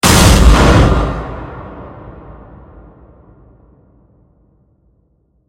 Звуки выстрелов
Громкий звук выстрела для отпугивания птиц